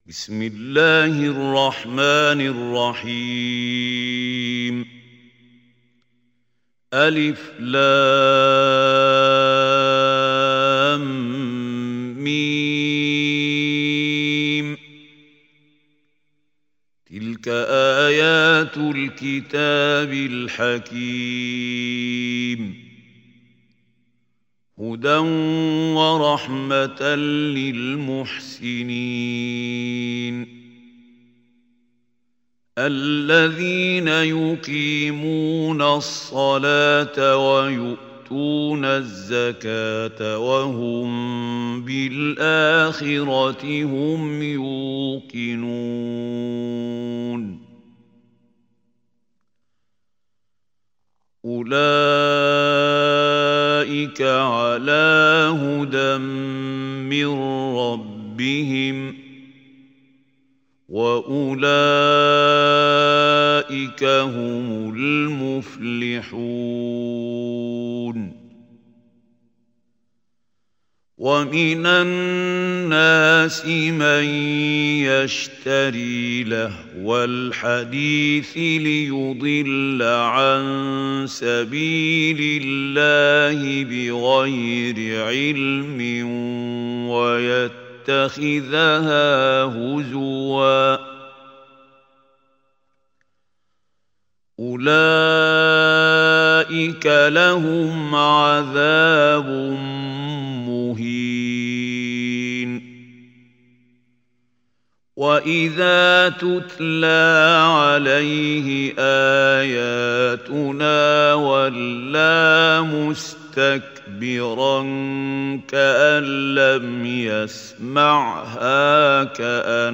Surah Luqman Recitation by Khalil Al Hussary
Surah Luqman is 31st chapter of Holy Quran. Listen online and download Quran recitation of Surah Luqman in the beautiful voice of Mahmoud Khalil Al Hussary.